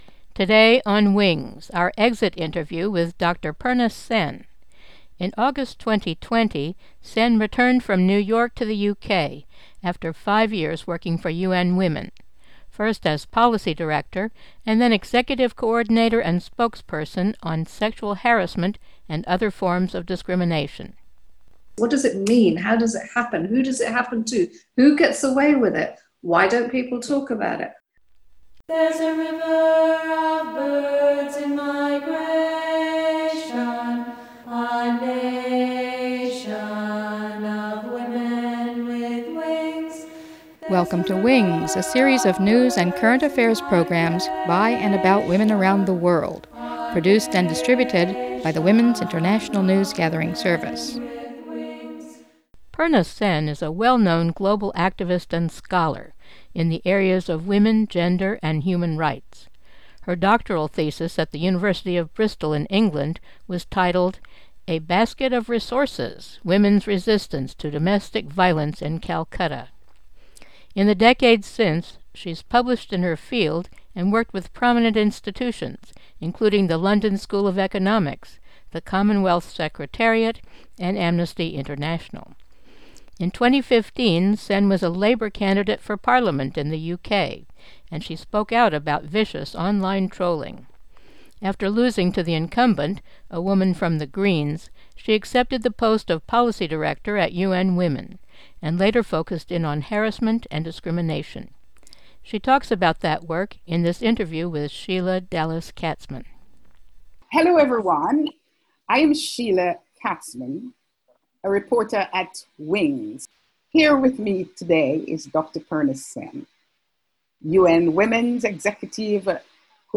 Interview upon leaving UN Women